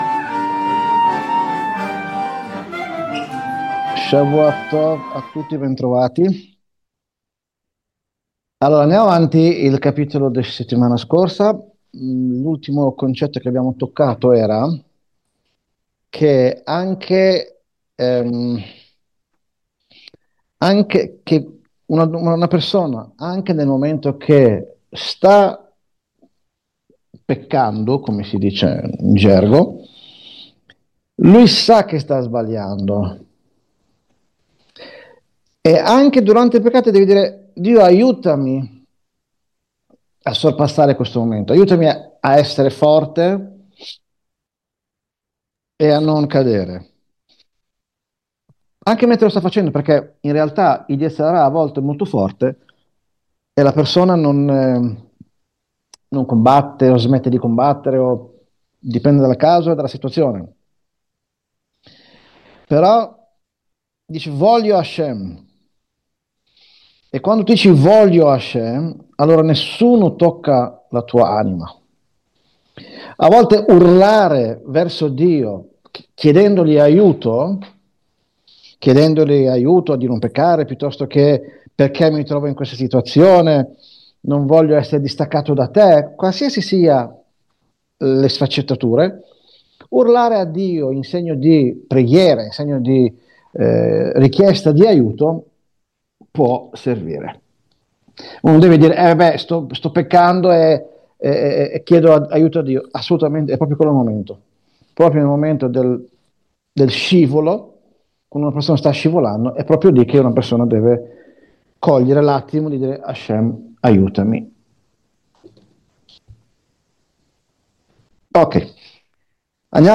Lezione